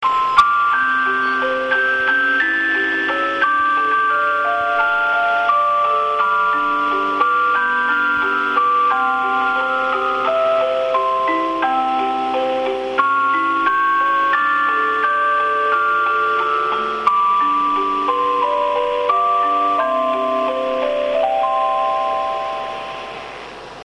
メロディー３
メロディー３とメロディー４は夜間高速バスおよび羽田空港直行バスの放送前に流れます。